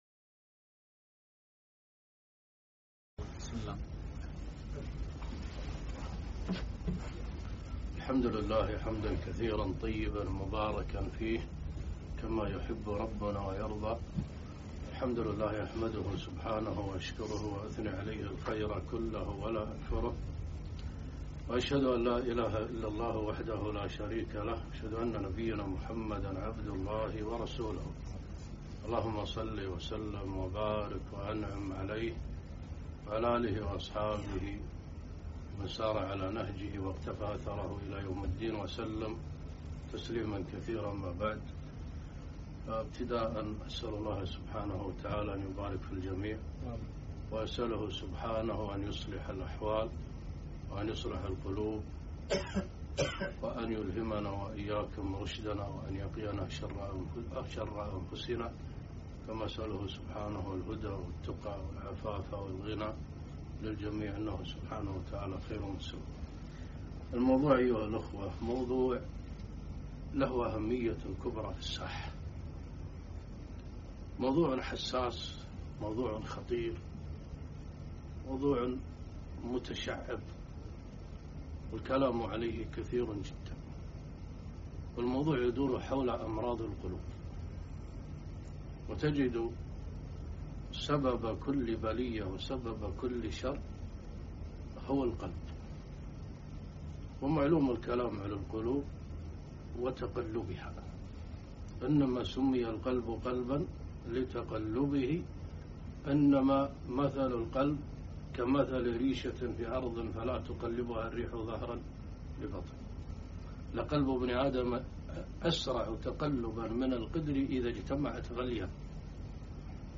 محاضرة - أمراض القلوب